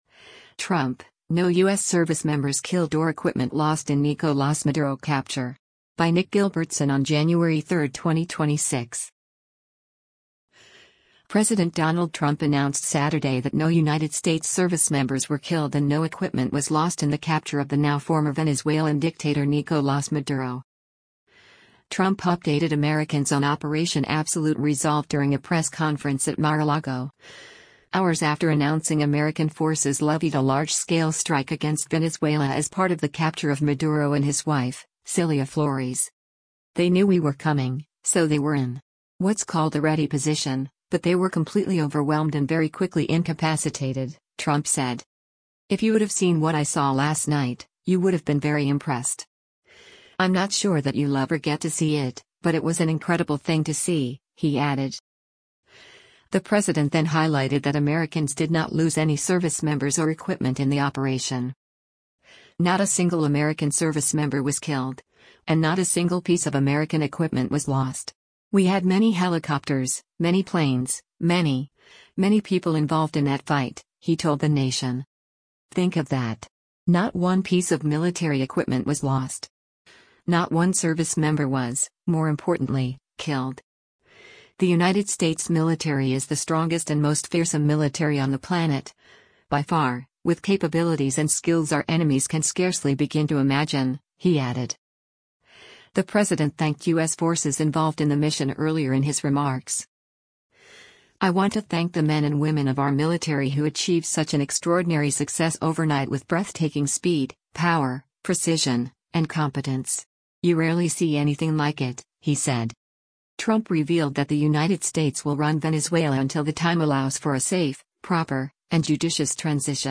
PALM BEACH, FLORIDA - JANUARY 03: U.S. President Donald Trump addresses the media during a
Trump updated Americans on Operation “Absolute Resolve” during a press conference at Mar-a-Lago, hours after announcing American forces levied “a large scale strike against Venezuela” as part of the capture of Maduro and his wife, Cilia Flores.